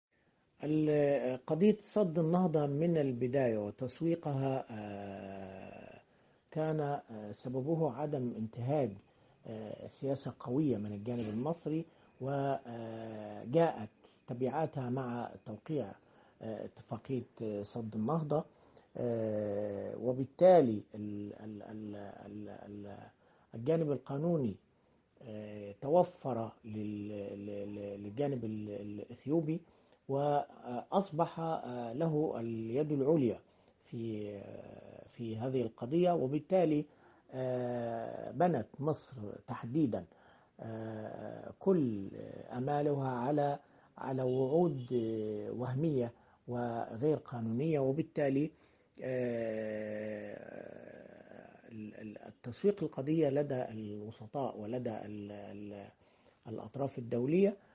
كاتب صحفي ومحلل سياسي